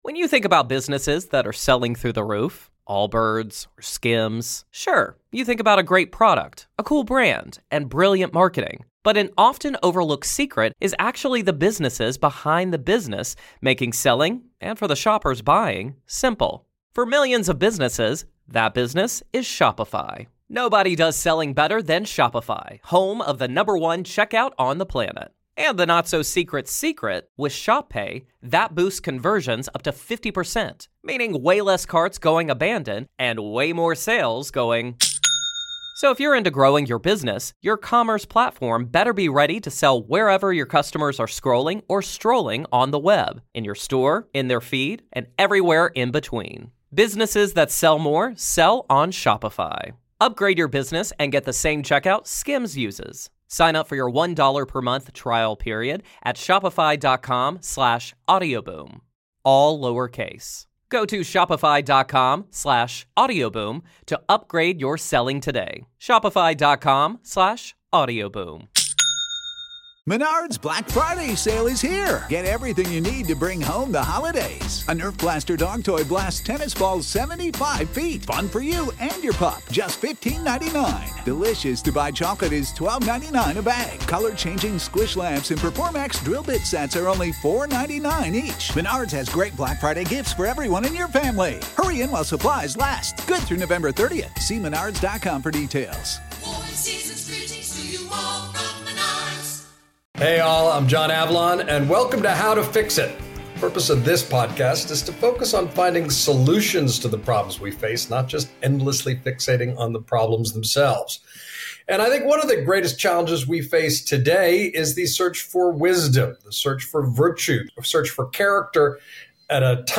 John Avlon sits down with Ryan Holiday for a deep dive into virtue, character, and what ancient wisdom can still teach a country drowning in cynicism.
Holiday opens up about his writing process, the influence of Robert Greene, and the books he returns to when he needs clarity. It’s a conversation about courage, justice, self-discipline, and why these old virtues feel newly urgent.